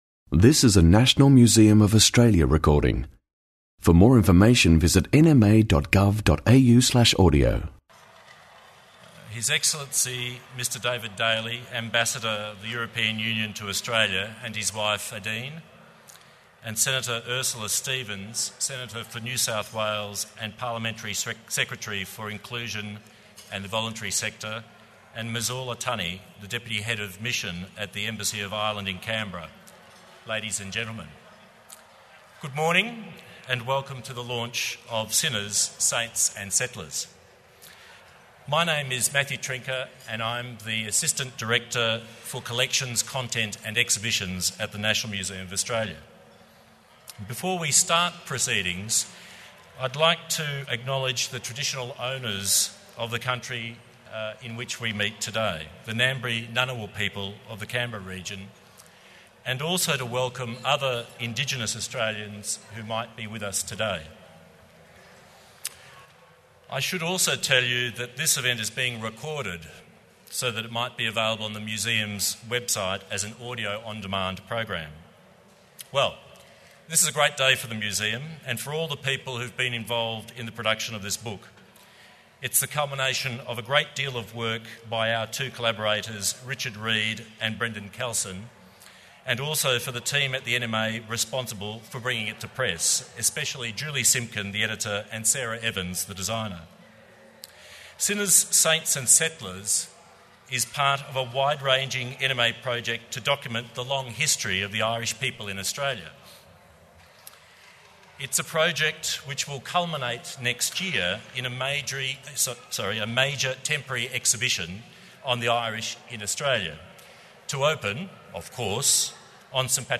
Sinners, Saints and Settlers: Book launch | National Museum of Australia